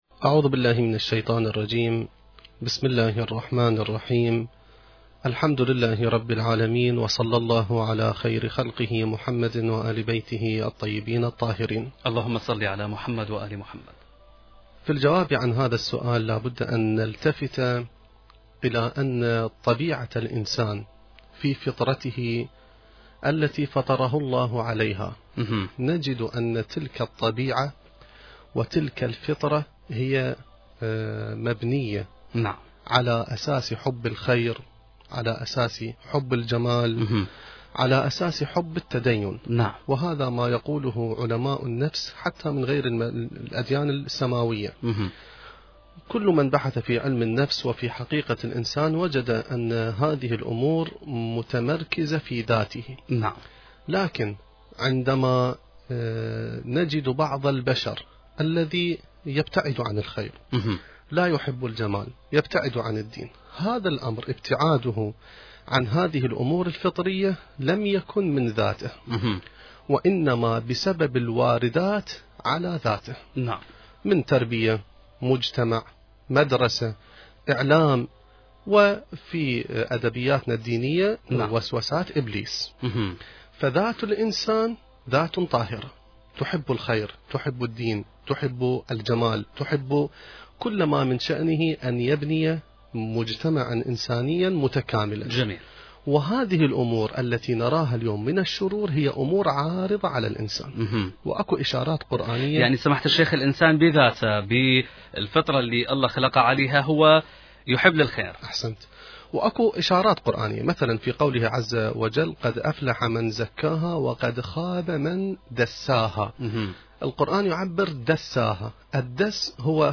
المكان: اذاعة العتبة العلوية المقدسة